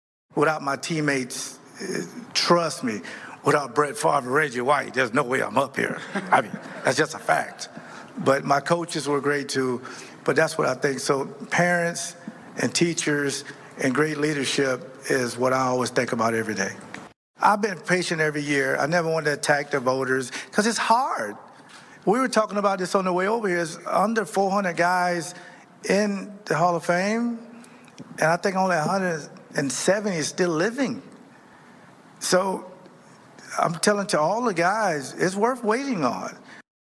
Following the ceremony, Butler met with reporters and said he was part of a special team in Green Bay, it took a lot of teamwork and while he had to wait 16 years since first becoming eligible, it was definitely worth that wait.